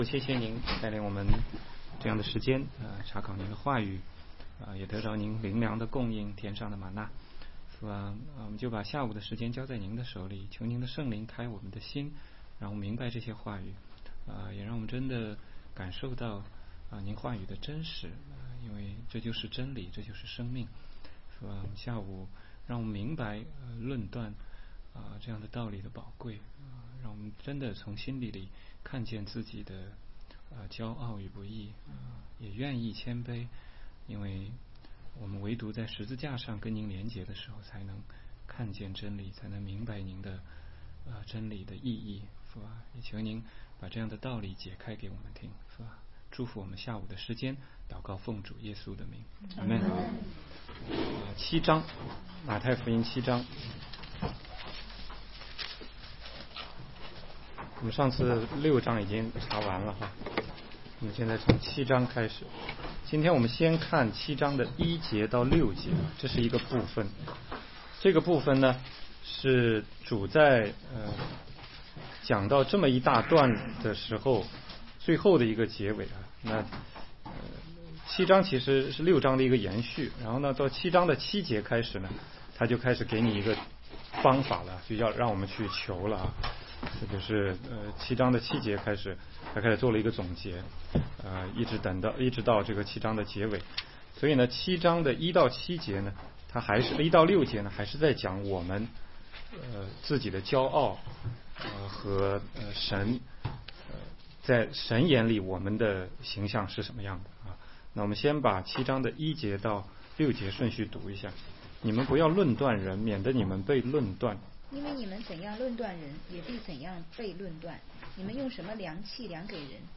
16街讲道录音 - 全中文查经